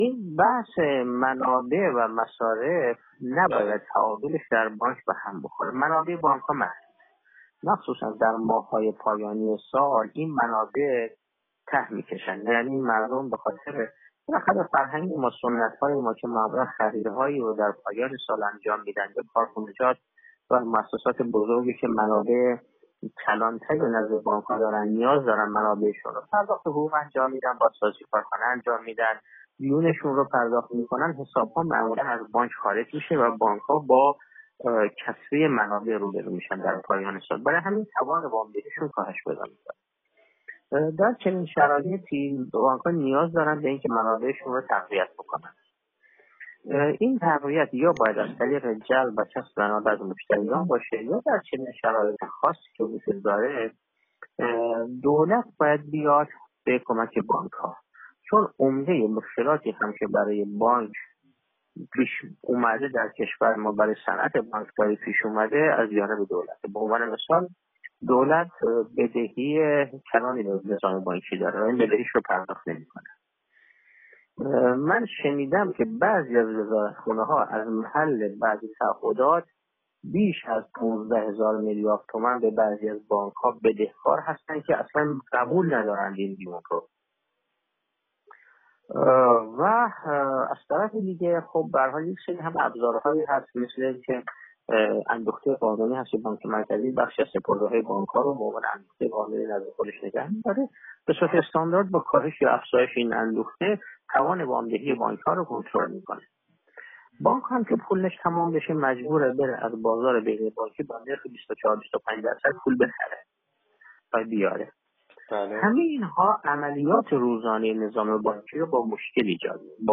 مرحبا در گفت‌وگو با ایکنا بیان کرد: